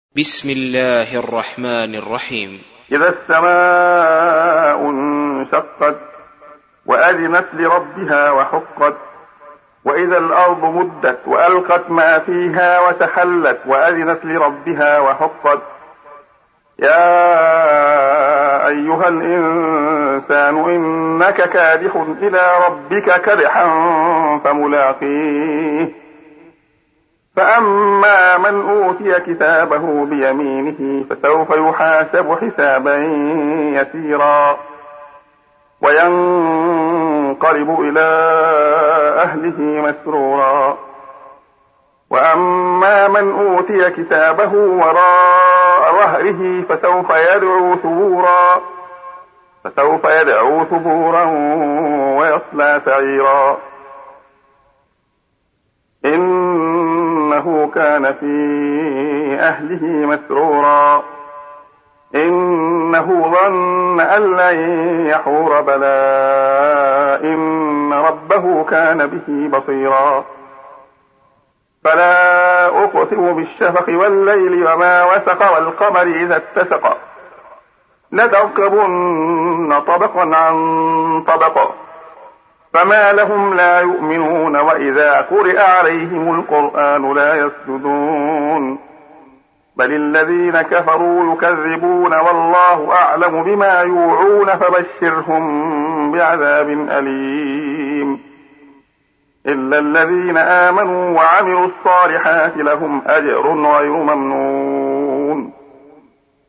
سُورَةُ الانشِقَاقِ بصوت الشيخ عبدالله الخياط